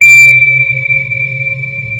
sonarPingWaterMedium2.ogg